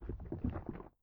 Custom sloshing noise system, allows individual bellies to be enabled to slosh louder and more frequently with how much is in them, no worries, made it so the system just bases chance on the highest volume at a given time and not spam sounds, also the sloshing noises are tied to digestion noises preference for those not wishing to hear that stuff.
walkslosh10.ogg